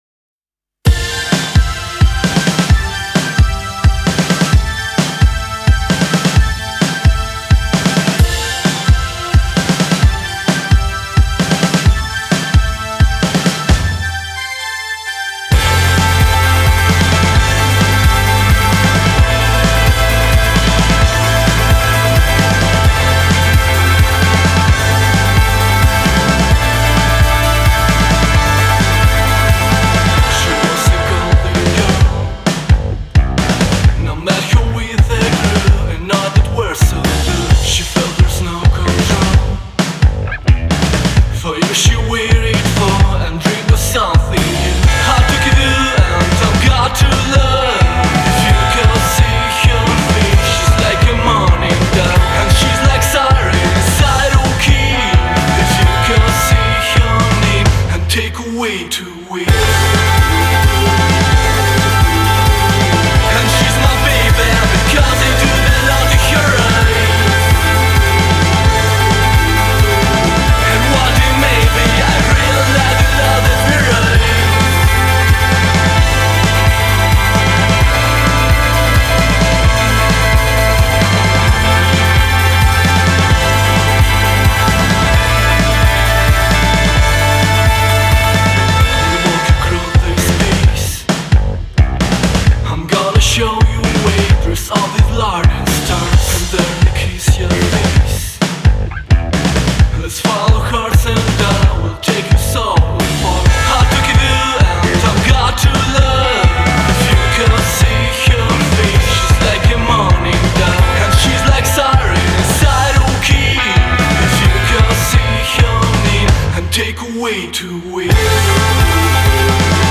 Indie Rock Electronic Synthpop Инди рок